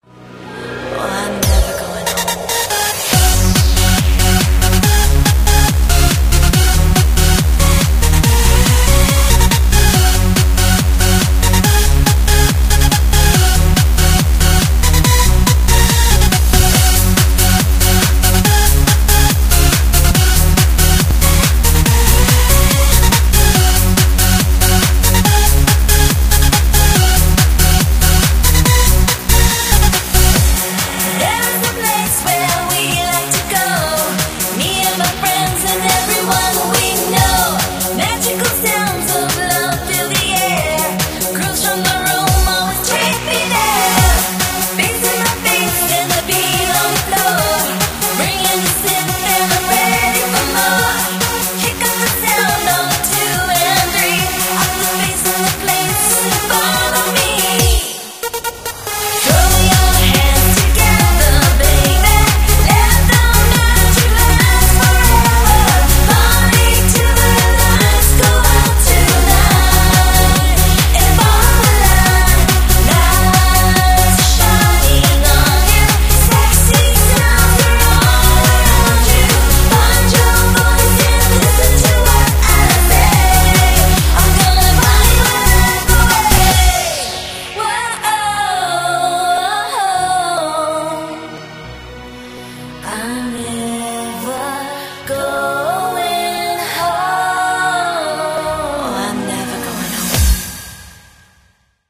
BPM141-141
Audio QualityPerfect (Low Quality)